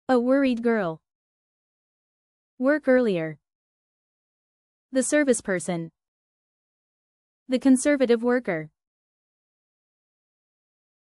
LET US PHRASE IT 複数の英単語で発音練習